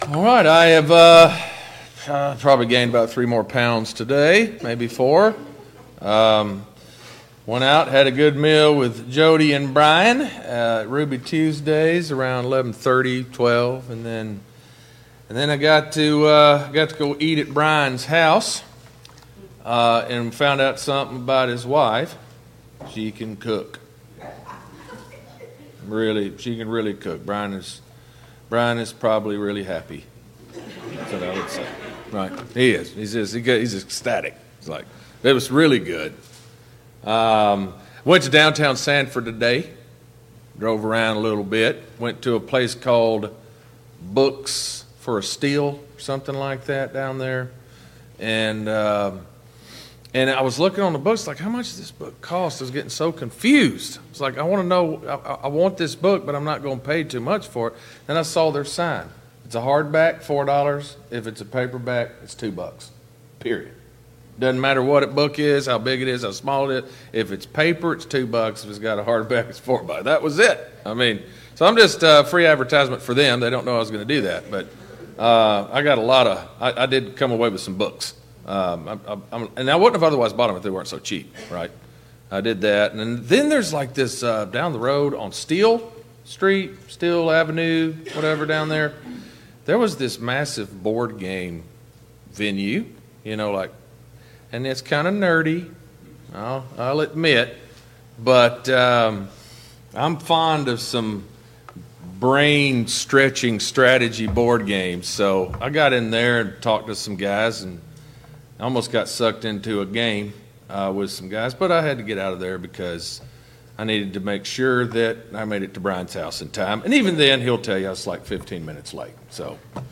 Service Type: Gospel Meeting Download Files Notes Topics: The Return of the Jesus Christ « 4.